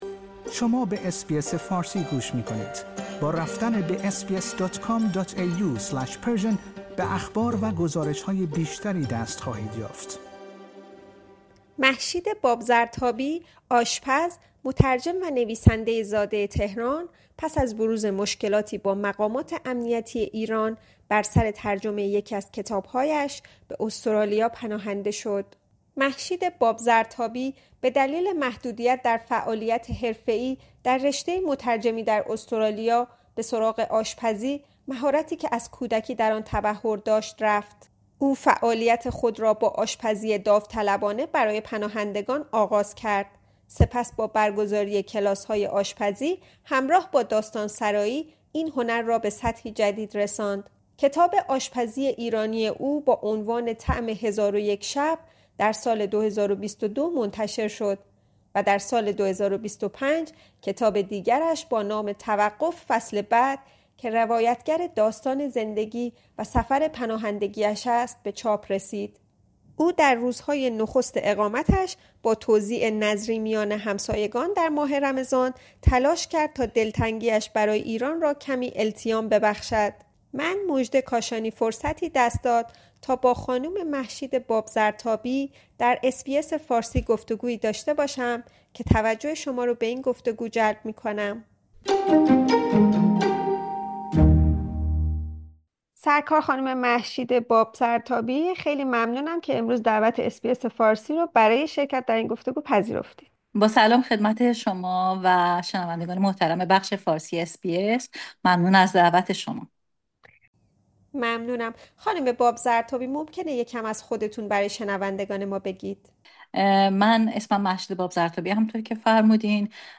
در گفت‌وگویی با برنامه فارسی رادیو SBS درباره آشنایی‌اش با آشپزی و پیوند آن با ماه رمضان در استرالیا سخن می‌گوید.